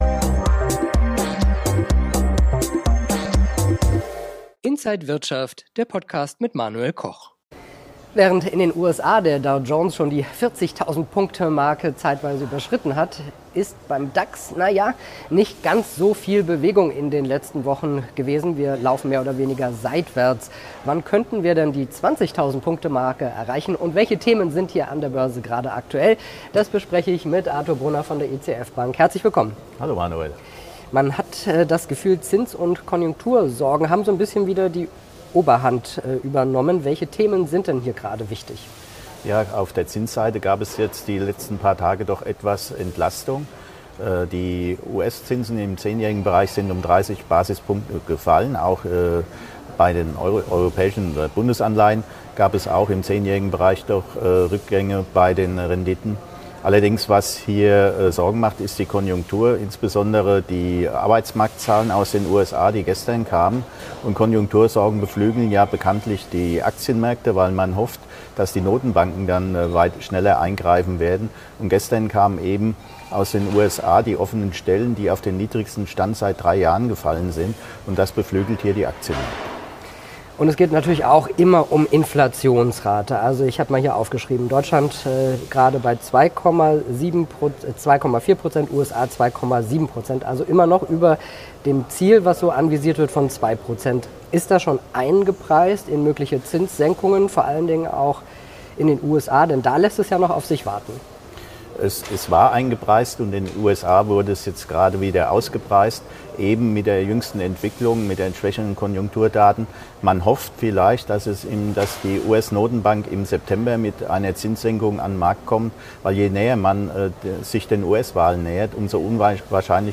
Alle Details im Interview von